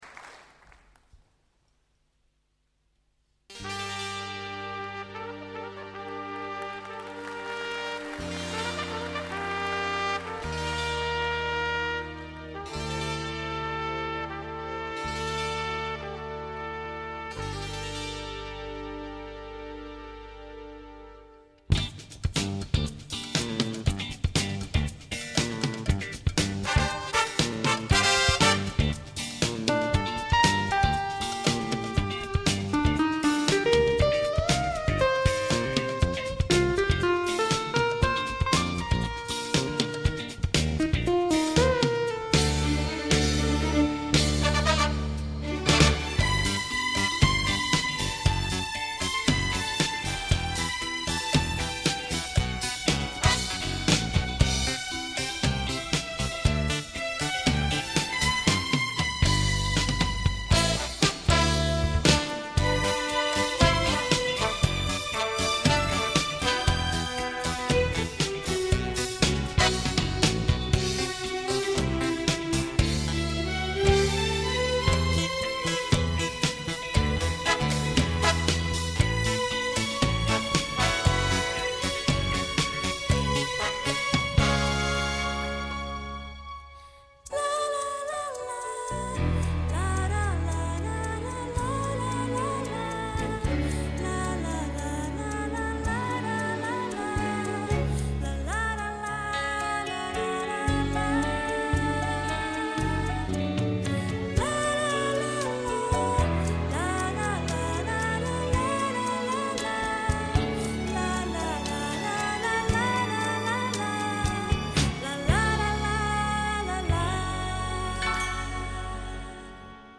1982年东京音乐会